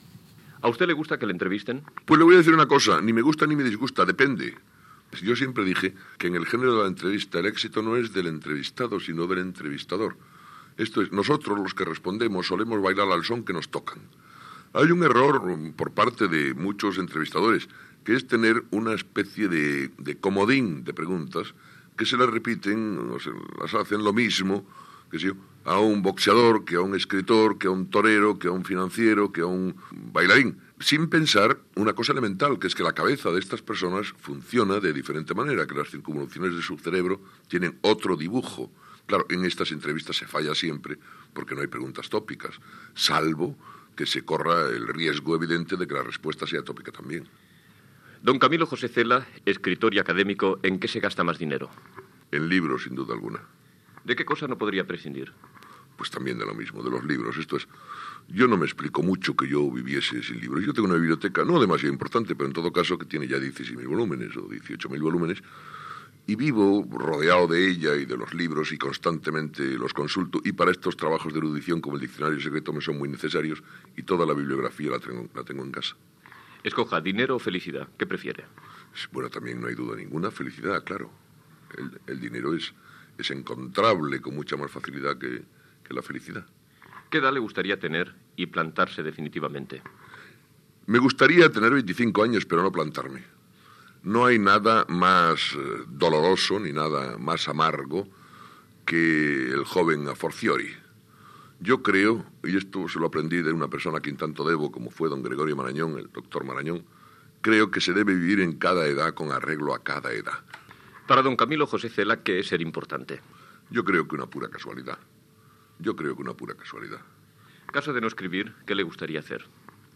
Entrevista a Camilo José Cela sobre la seva opinió de les entrevistes i les seves preferències vitals